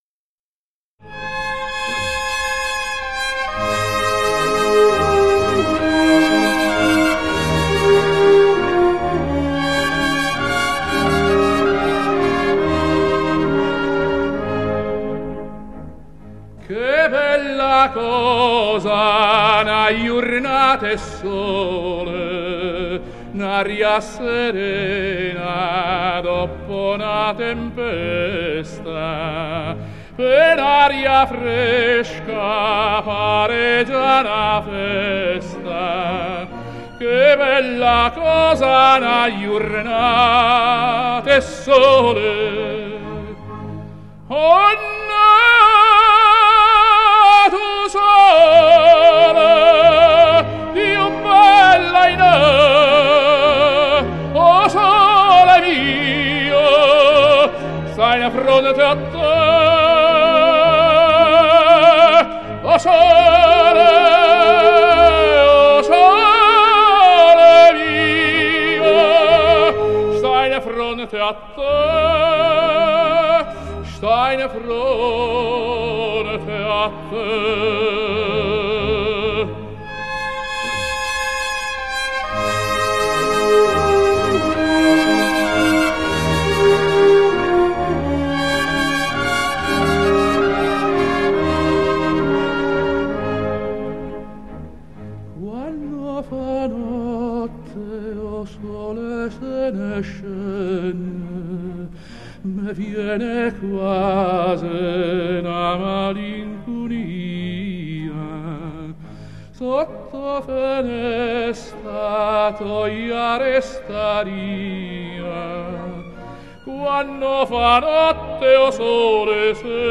版 本：LIVE